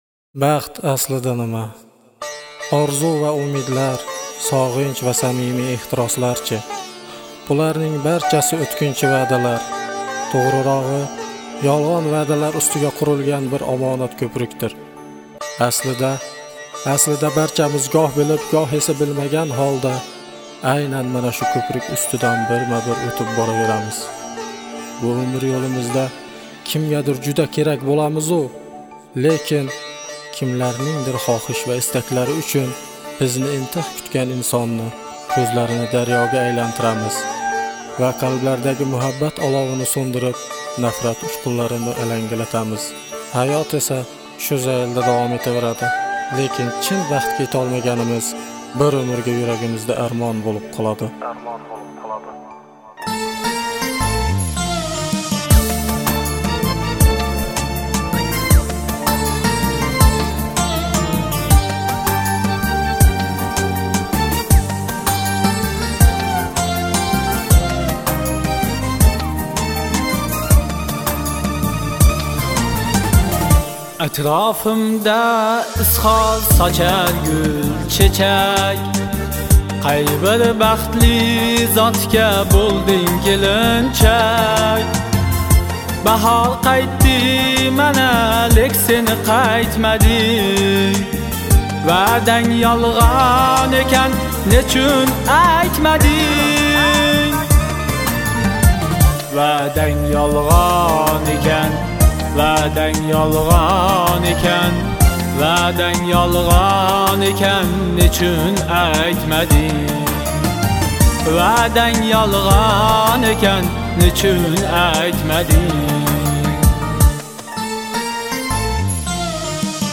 Жанр: Ошабам кушиклари